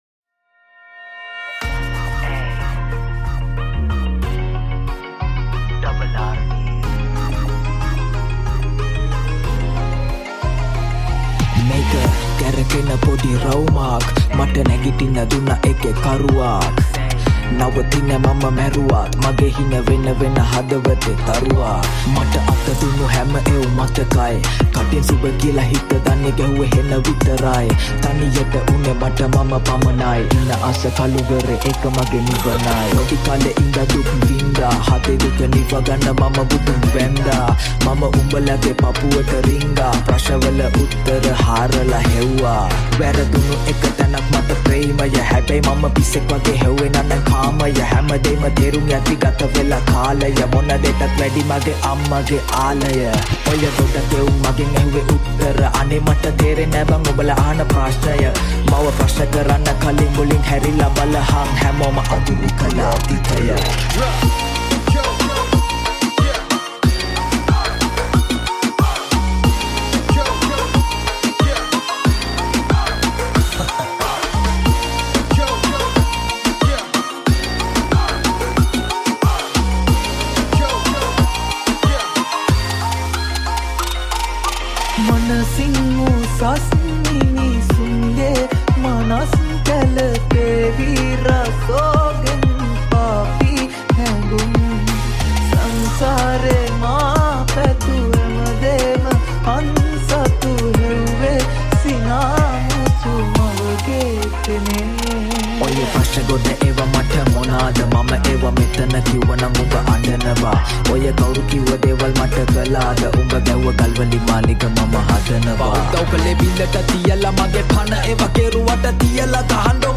High quality Sri Lankan remix MP3 (2.5).